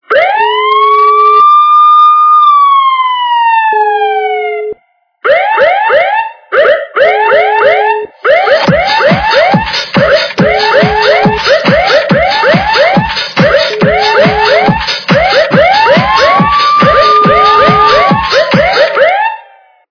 » Звуки » звуки для СМС » Сирена - Понтовая
Звук Сирена - Понтовая